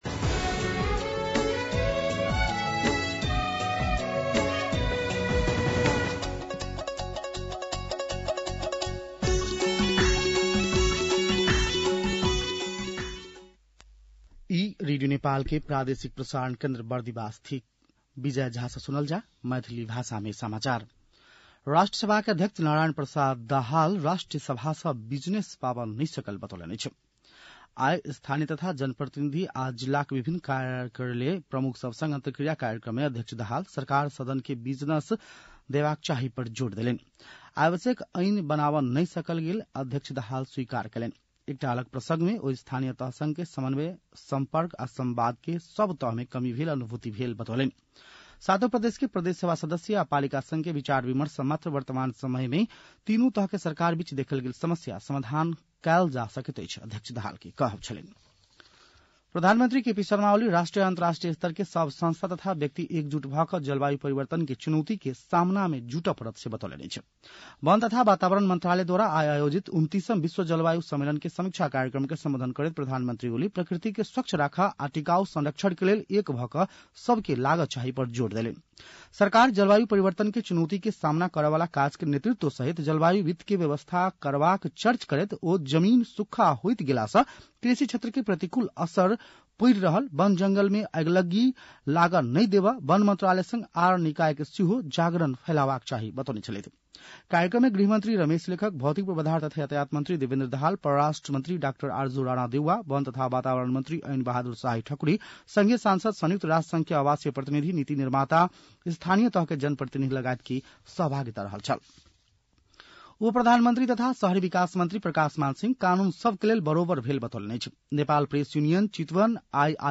मैथिली भाषामा समाचार : १२ पुष , २०८१